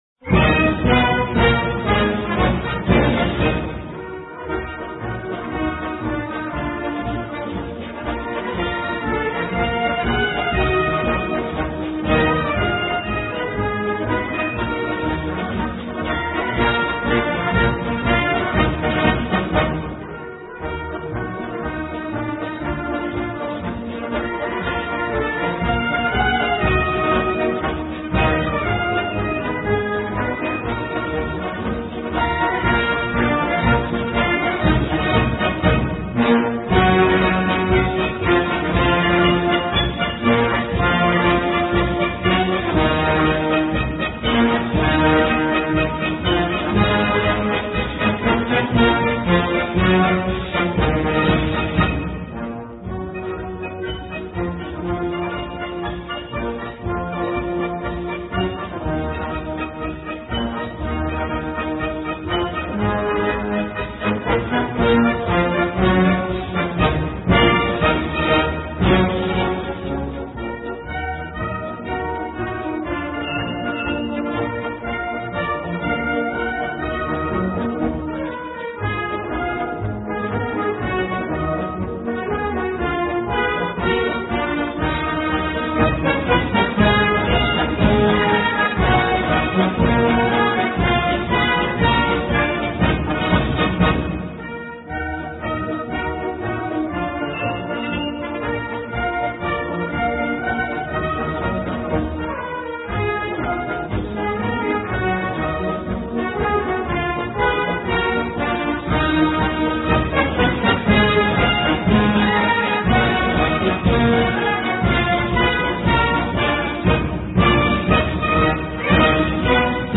У него получился великолепный военный марш.
Чеканные звуки этого марша провожали русских солдат на фронт: